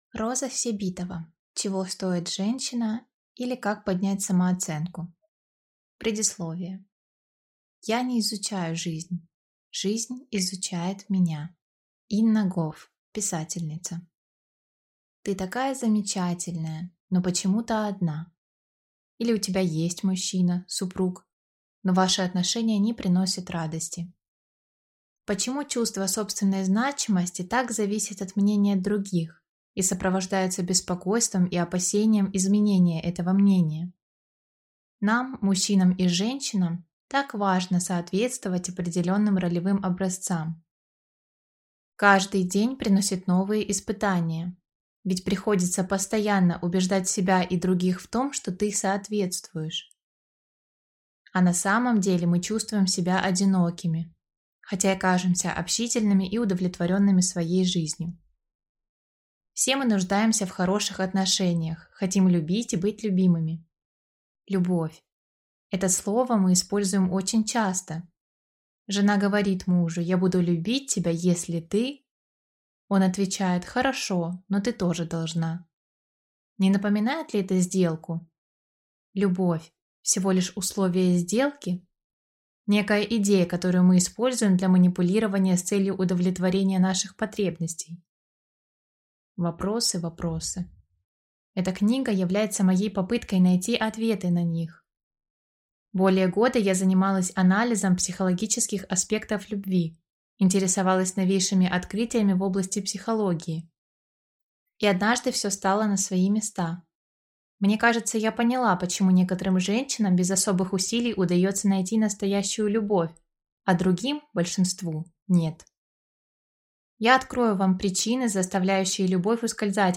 Аудиокнига Чего стоит женщина, или Как поднять самооценку | Библиотека аудиокниг